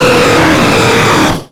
Cri de Rayquaza dans Pokémon X et Y.